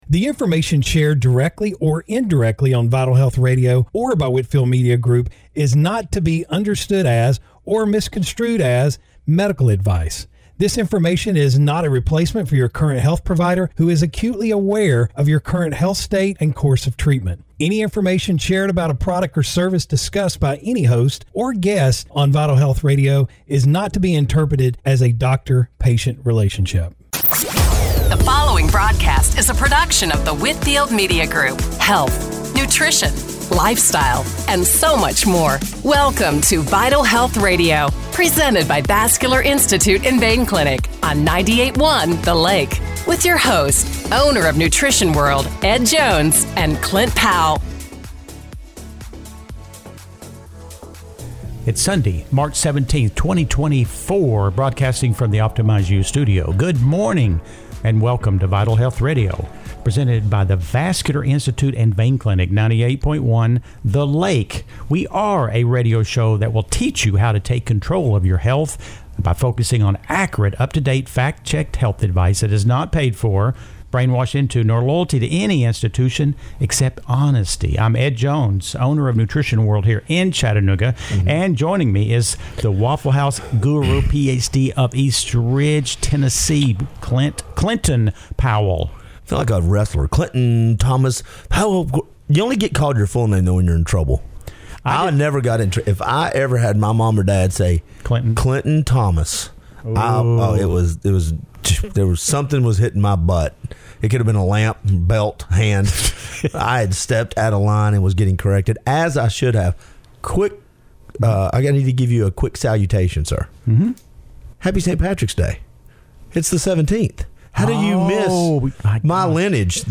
Radio Show – March 17, 2024 – Vital Health Radio